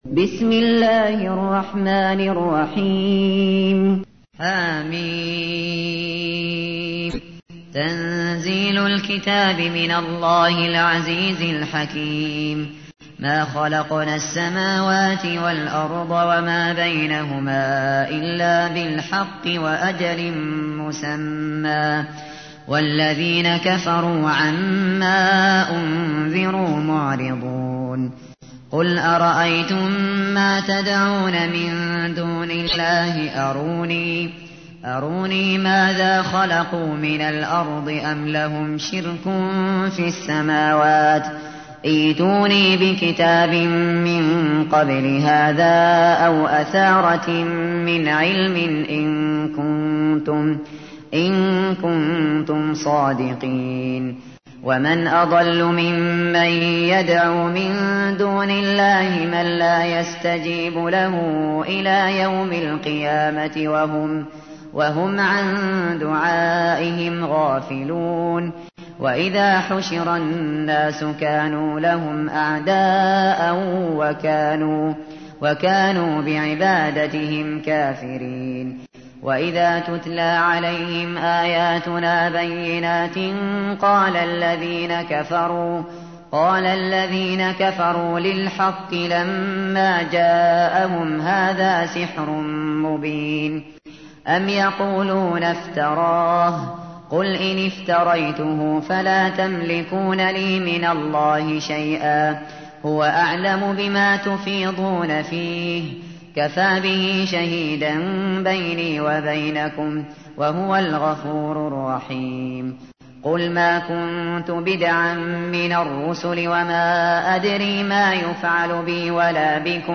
تحميل : 46. سورة الأحقاف / القارئ الشاطري / القرآن الكريم / موقع يا حسين